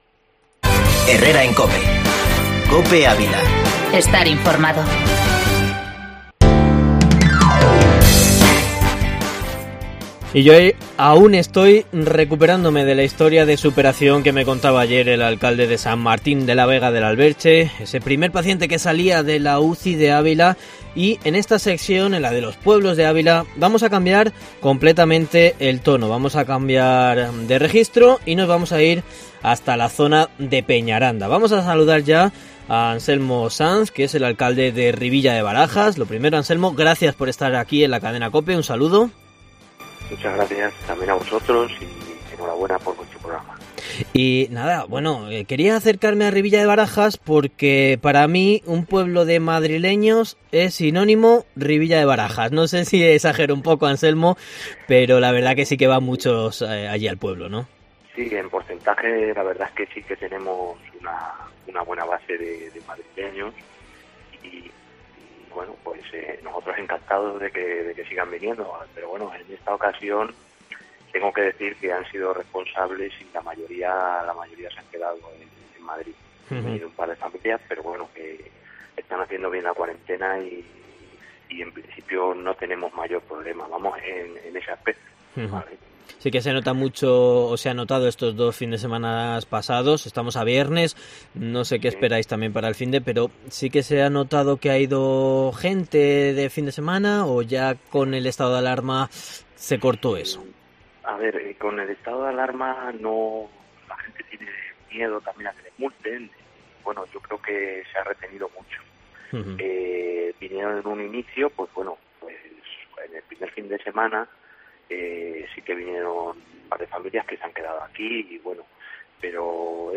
Alcalde de Rivilla de Barajas, Anselmo Sanz, en COPE Ávila